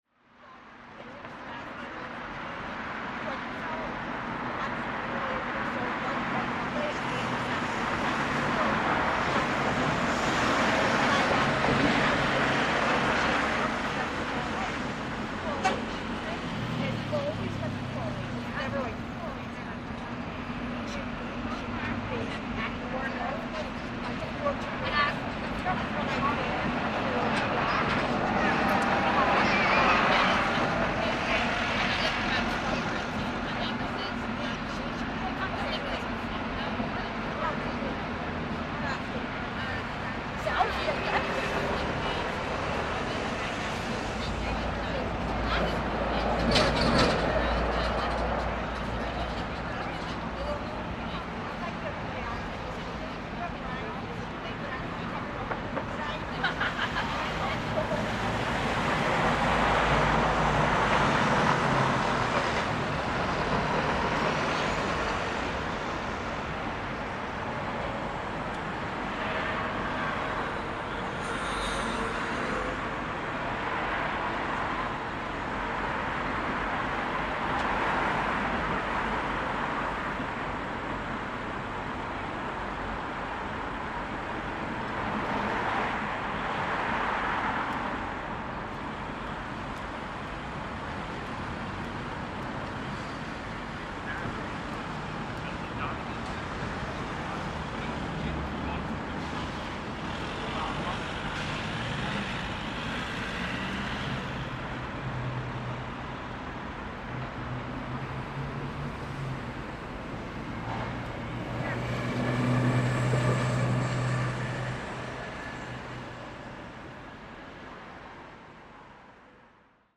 Belfast City Hall during lockdown, 16.10.20
Recording of more locals and visitors passing at the junction that intertwines city centre, daily shopping, and commuters. There is a lot more chatter in the nearby area as people are heading home due to the new Lockdown 2 in Belfast beginning.